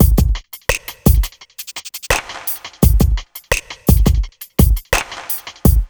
1TI85BEAT3-R.wav